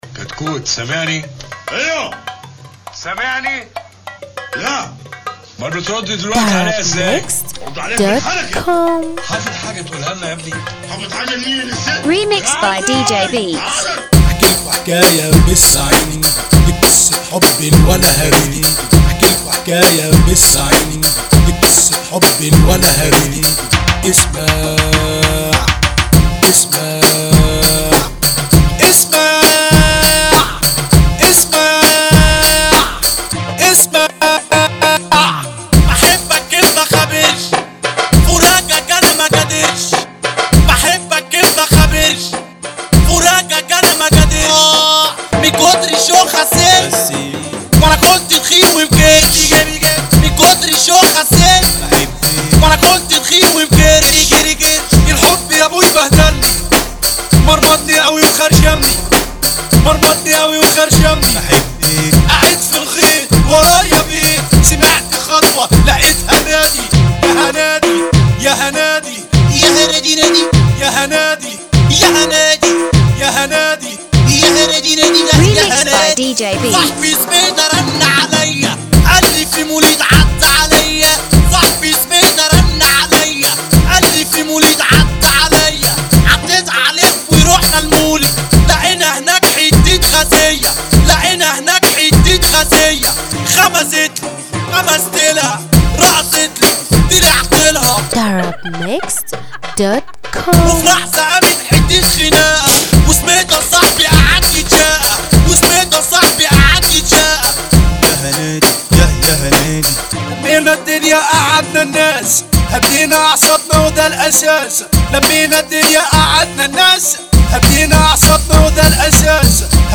Mahrgan